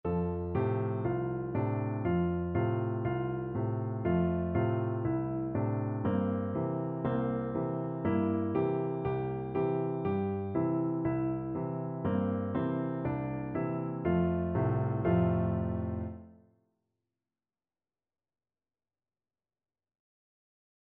Free Sheet music for Piano Four Hands (Piano Duet)
4/4 (View more 4/4 Music)
F major (Sounding Pitch) (View more F major Music for Piano Duet )
Molto Allegro (View more music marked Allegro)
Traditional (View more Traditional Piano Duet Music)